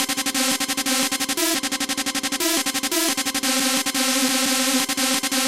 雷夫合成器
描述：175 BPM。我真的不知道该把它放在什么类型下。我就把它放在House下，因为它是"Hard House.quot。
标签： 175 bpm House Loops Synth Loops 944.75 KB wav Key : Unknown
声道立体声